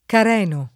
careno [ kar $ no ]